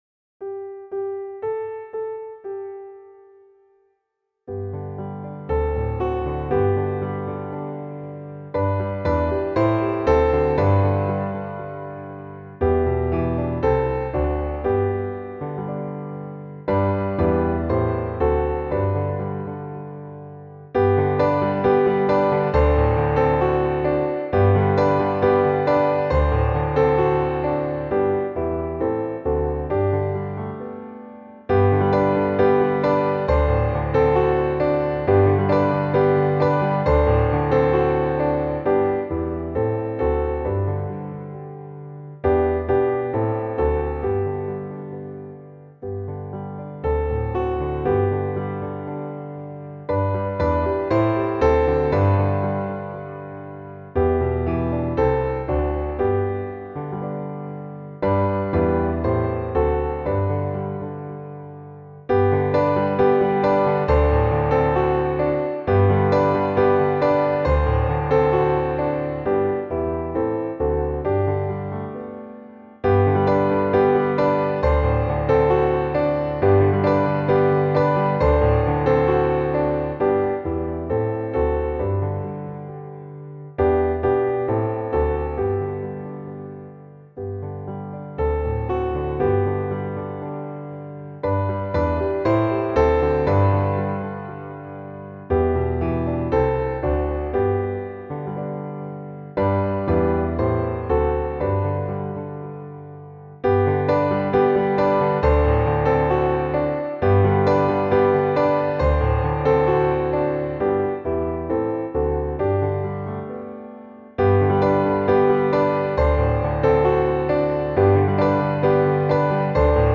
Aby ułatwić przygotowanie się do nagrania, poniżej prezentujemy podkłady muzyczne, w trzech wersjach.